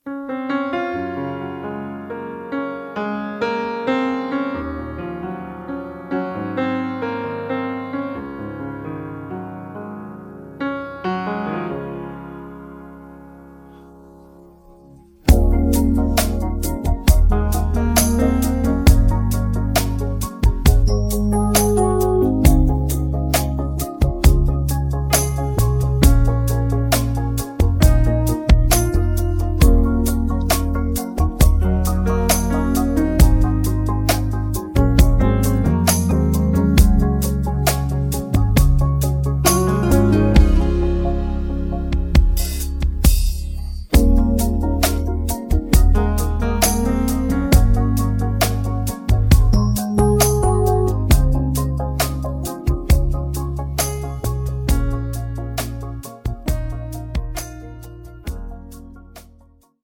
음정 -1키 5:13
장르 가요 구분 Voice Cut